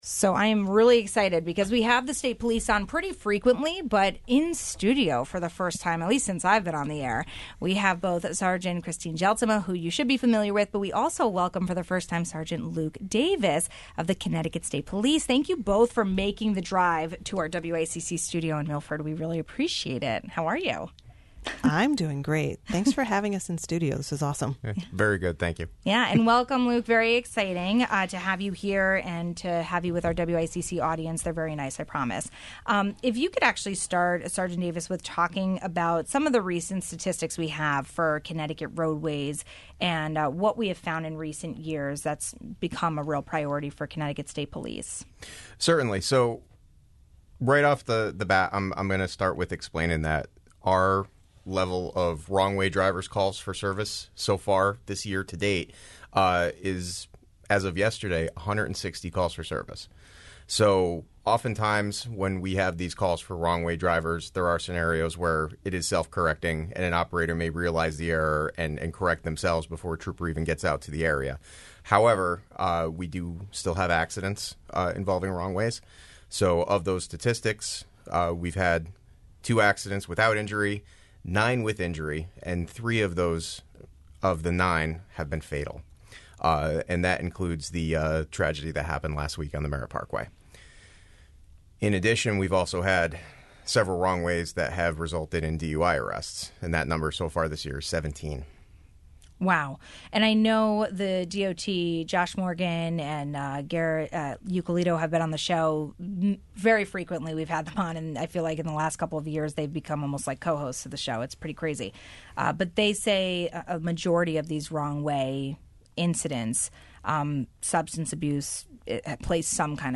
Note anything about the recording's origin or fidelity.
Wrong-way crashes, click it or ticket and more motorcycles on our roadways. We talked with Connecticut State Police in studio about driver behavior and statistics for our highway incidents.